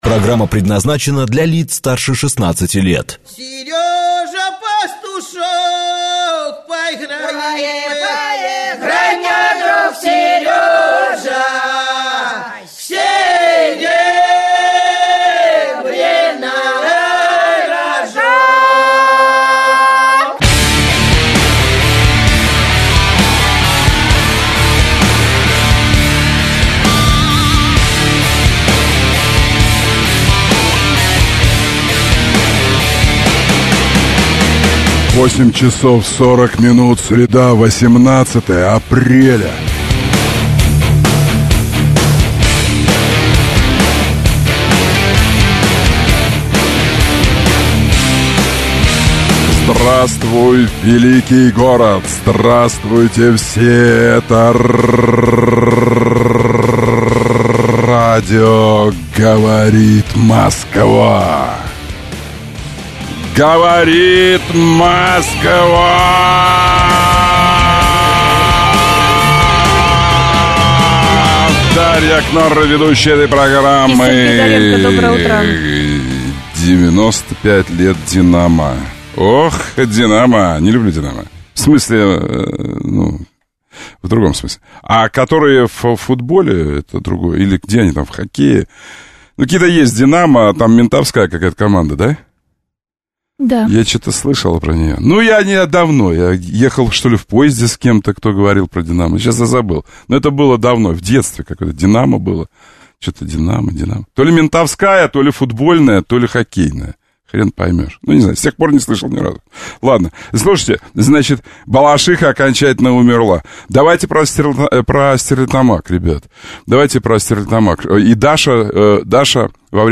Авторская программа Сергея Доренко. Обсуждение самых актуальных общественно-политических тем, телефонные голосования среди слушателей по самым неоднозначным и острым вопросам, обзоры свежей прессы.